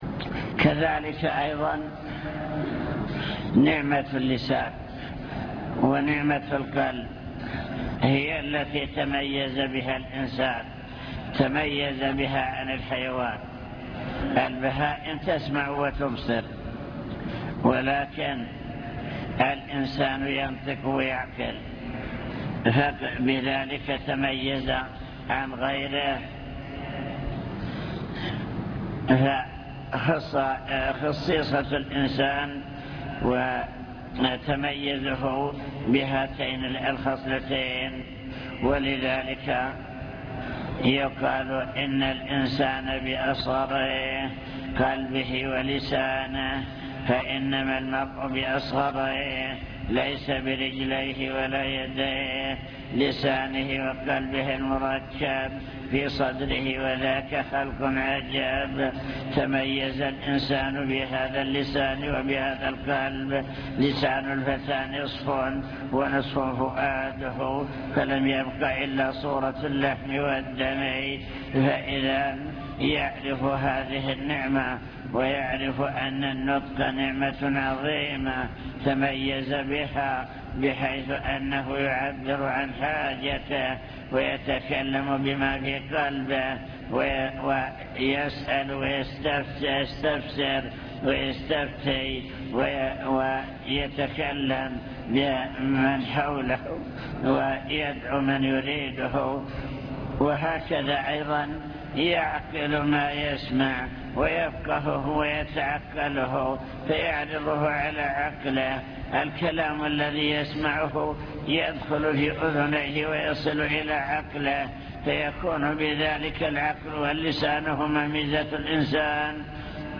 المكتبة الصوتية  تسجيلات - محاضرات ودروس  محاضرة بعنوان شكر النعم (1) ذكر نماذج لنعم الله تعالى العامة وكيفية شكرها